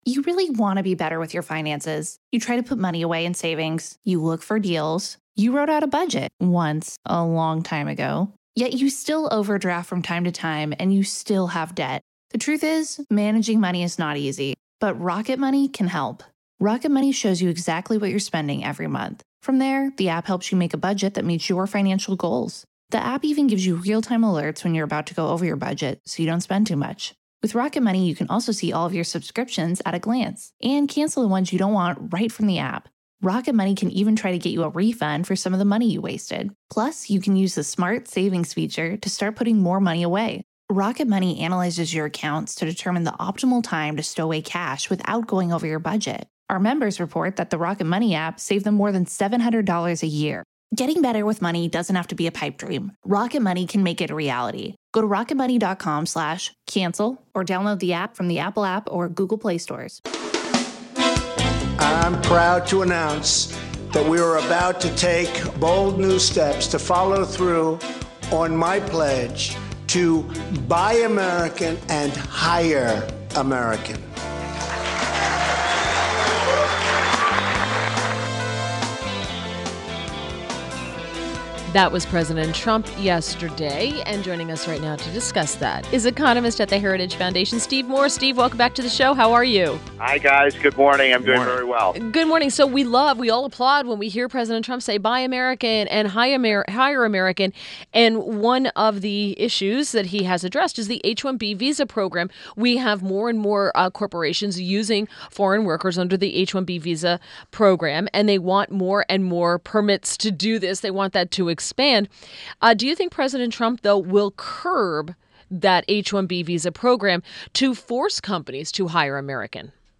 INTERVIEW — STEVE MOORE – Economist at The Heritage Foundation